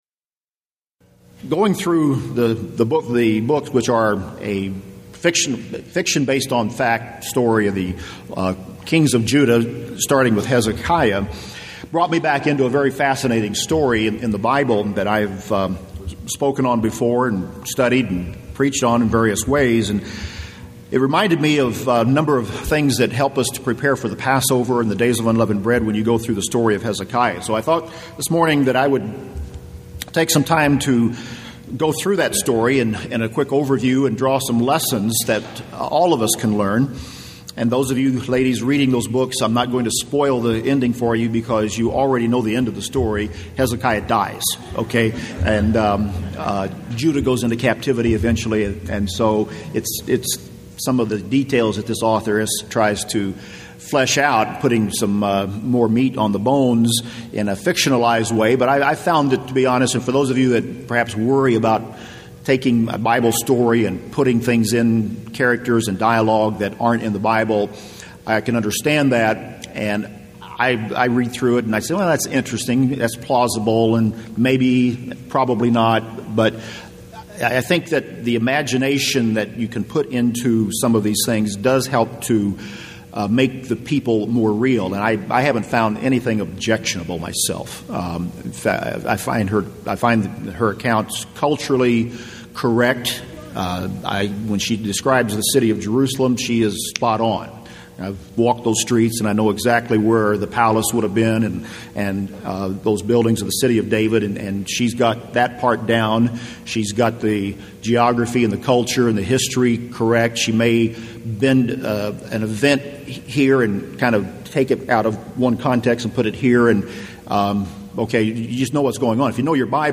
UCG Sermon Transcript